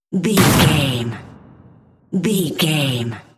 Cinematic stab hit trailer
Sound Effects
Atonal
heavy
intense
dark
aggressive